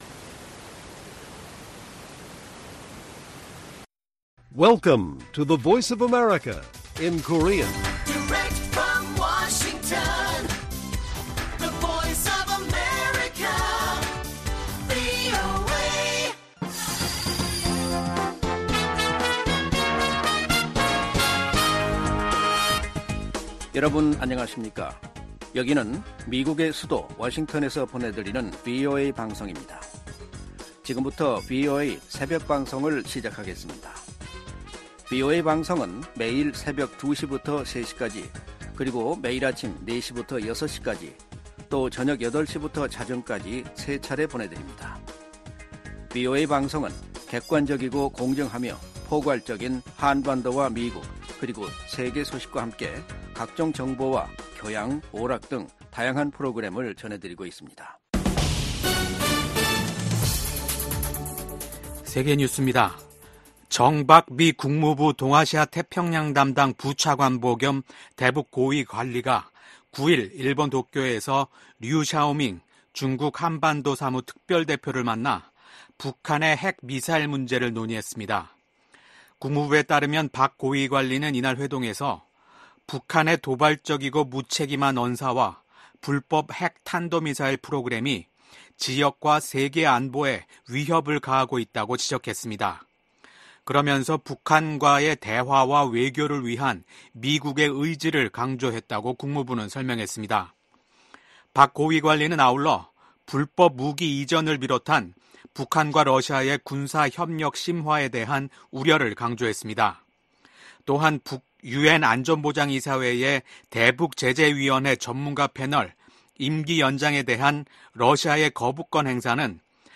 VOA 한국어 '출발 뉴스 쇼', 2024년 5월 10일 방송입니다. 윤석열 한국 대통령은 취임 2주년 기자회견을 열고 오는 11월 미국 대통령 선거 결과와 관계없이 미한 양국간 동맹관계는 변치 않을 것이라고 말했습니다. 지난 두 달여 동안 북한 남포 유류 항구에 최소 18척의 유조선이 입항한 것으로 나타났습니다. 미 국무부는 북한이 사이버 범죄와 암호화폐 탈취 등 악의적인 사이버 활동을 확대하고 있다고 지적했습니다.